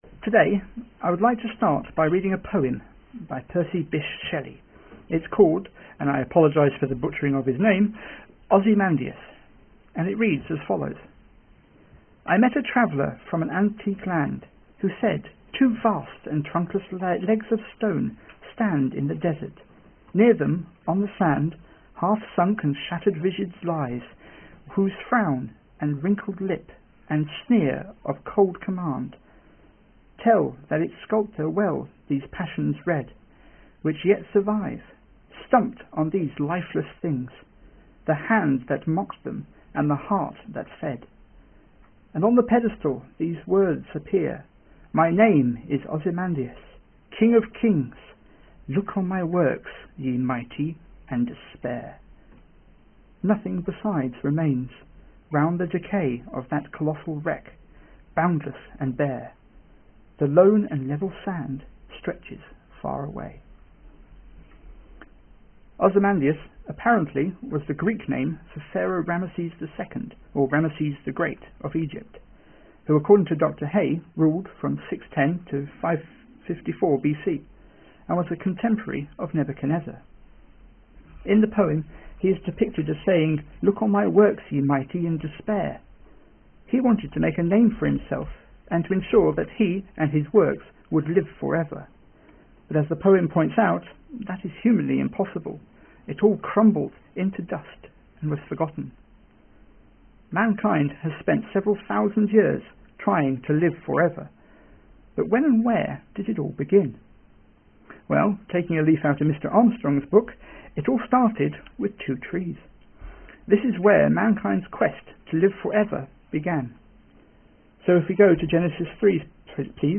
Offertory